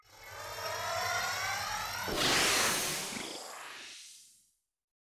Psychic_shock_lance_charge_fire.ogg